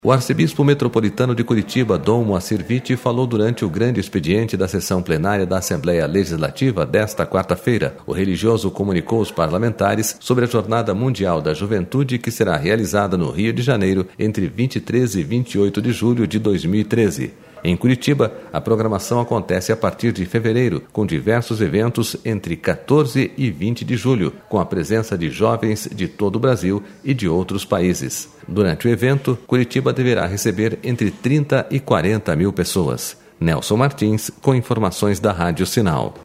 Arcebispo de Curitiba fala aos deputados sobre a Jornada Mundial da Juventude
O arcebispo metropolitano de Curitiba, Dom Moacyr José Vitti, falou durante o Grande Expediente da sessão plenária da Assembleia Legislativa desta quarta-feira.//